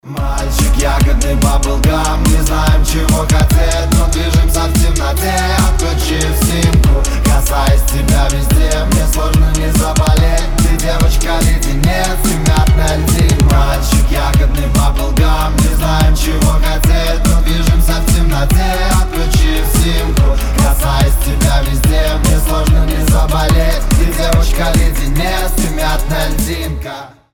поп
громкие
Хип-хоп
быстрые